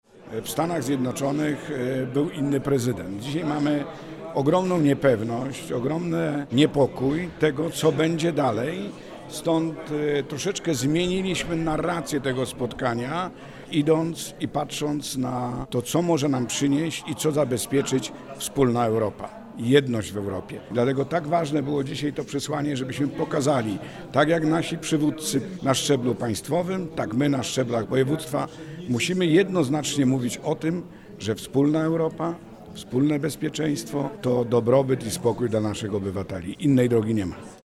W sali Sejmiku Województwa Dolnośląskiego we Wrocławiu odbyło się trójstronne spotkanie prezydiów parlamentów regionalnych Dolnego Śląska, Autonomicznej Republiki Adżarii oraz Kraju Związkowego Brandenburgii.